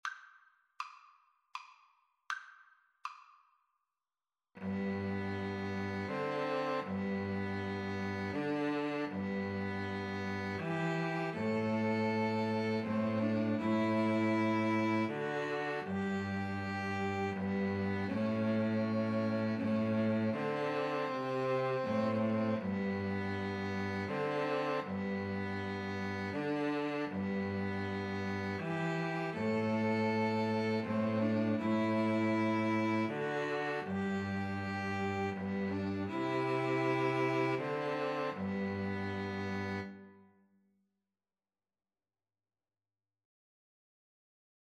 Christmas
Andante
3/4 (View more 3/4 Music)
2-Violins-Cello  (View more Easy 2-Violins-Cello Music)